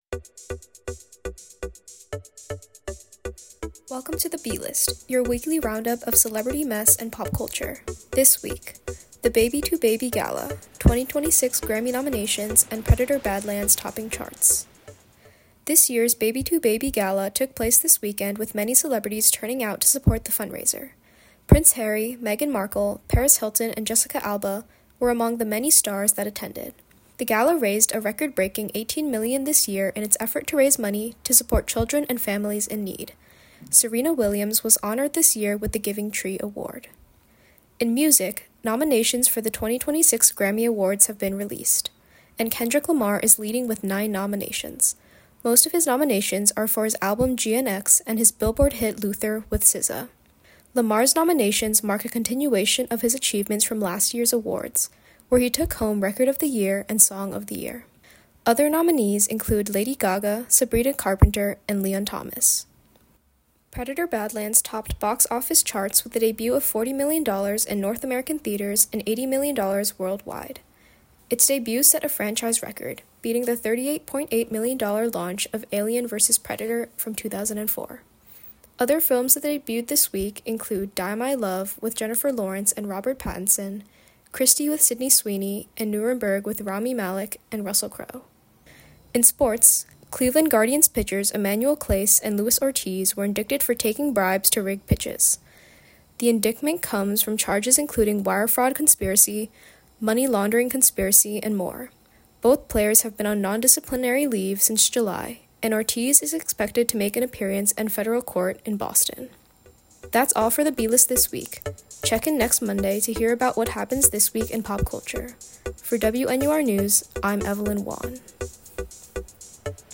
Theme music is from MixKit “What What?! Wowow!” by Michael Ramir C.